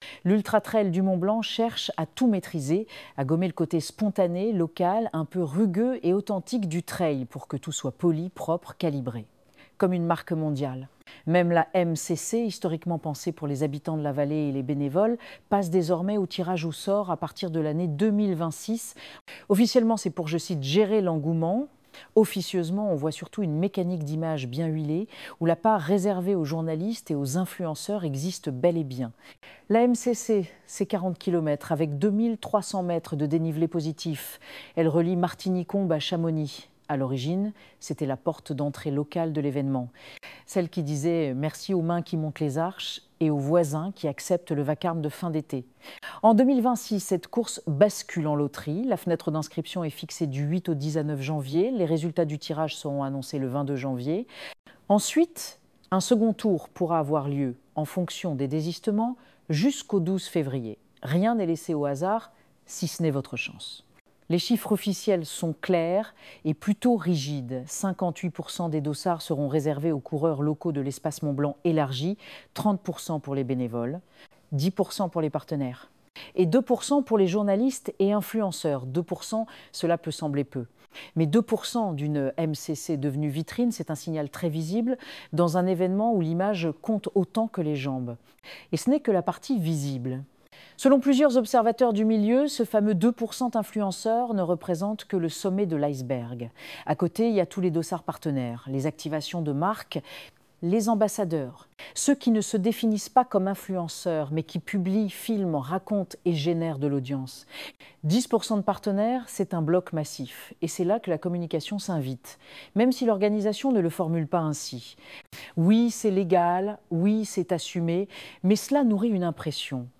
Ecouter cet article sur les inscriptions par tirage au sort à la MCC UTMB